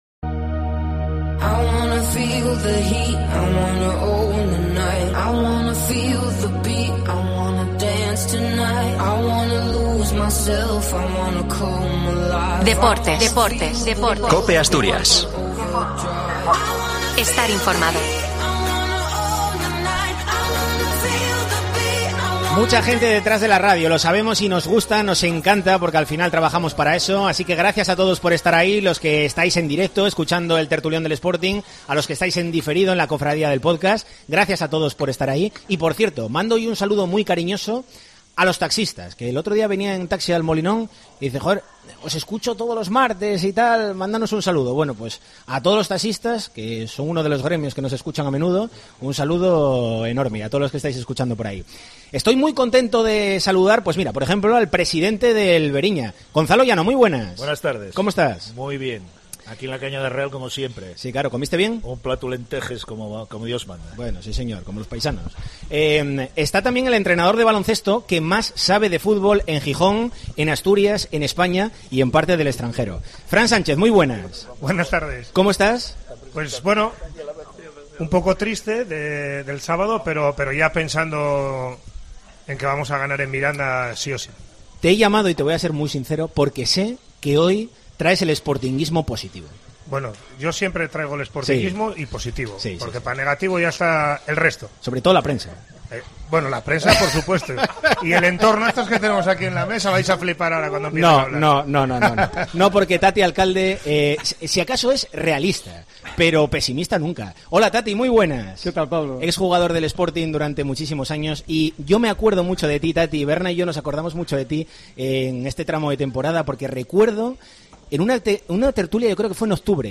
En el capítulo de este martes de 'El Tertulión del Sporting' desde La Cañada Real Molinón , debatimos acerca de la planificación de plantilla del Sporting . Los pros y los contras de una plantilla diseñada, a priori, para objetivos diferentes a los del ascenso.